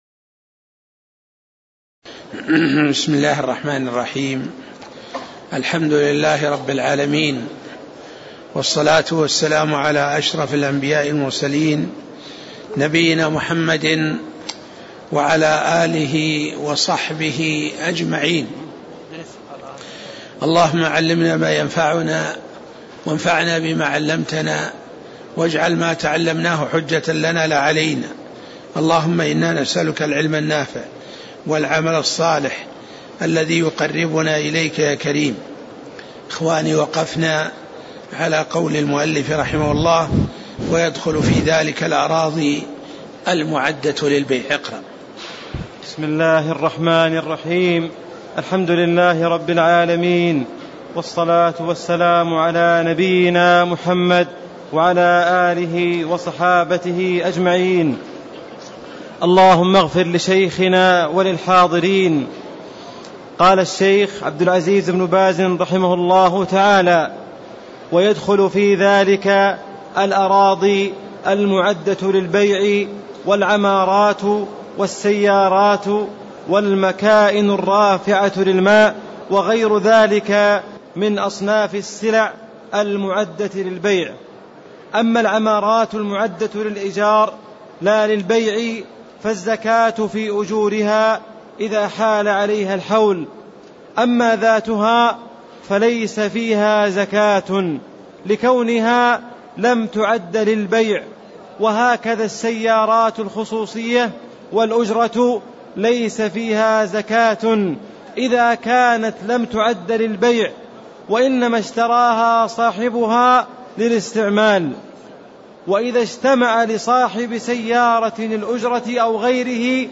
تاريخ النشر ٢٣ شعبان ١٤٣٦ هـ المكان: المسجد النبوي الشيخ